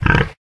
Divergent/boar_threaten_1.ogg at 58aea60d01aefcb528a3b2ed4647d2e3e1b520e4
boar_threaten_1.ogg